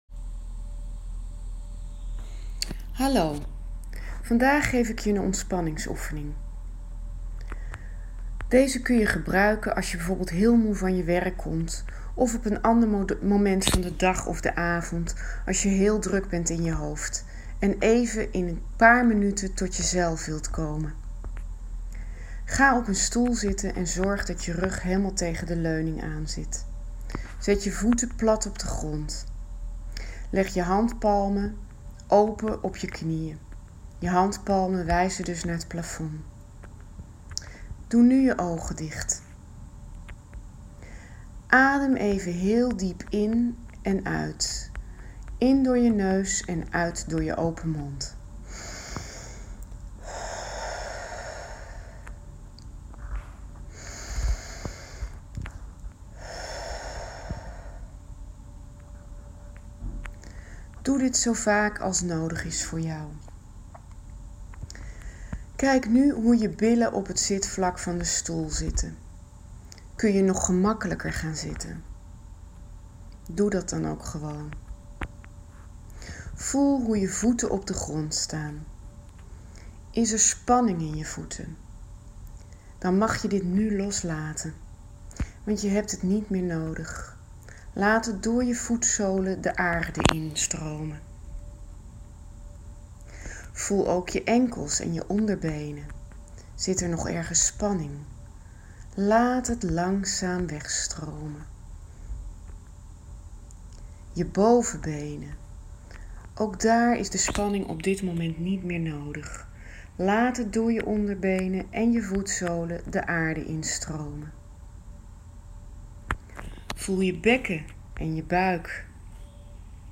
Ontspanning- en visualisatieoefeningen